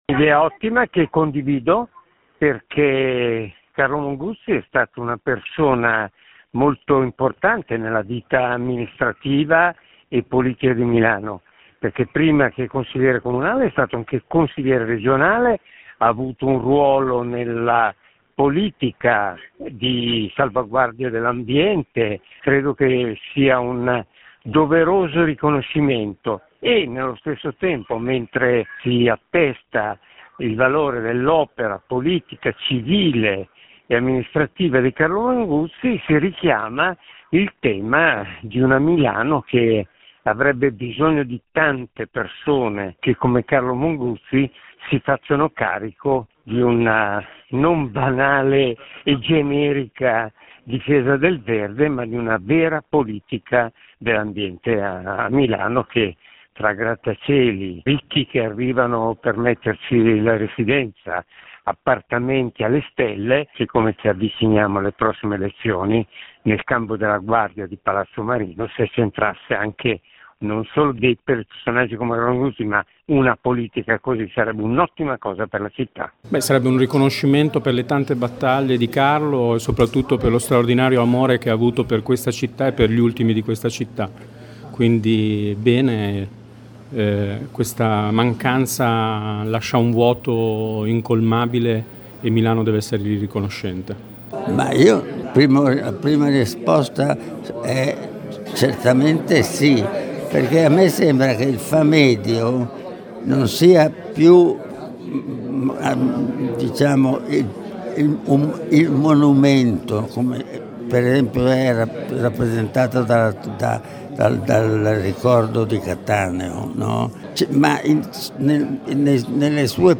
Anche Radio Popolare appoggia questa proposta, così come alcune personalità della città a cui abbiamo chiesto un commento.